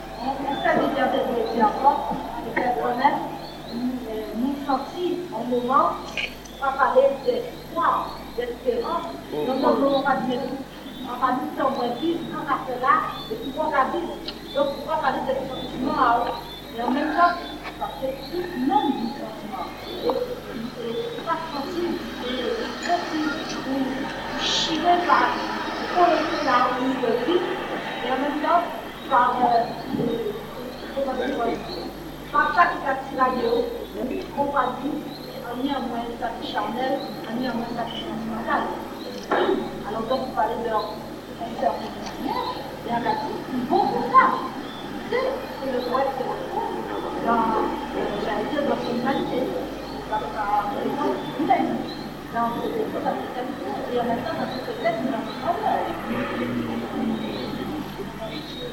Version audio du film de la soirée.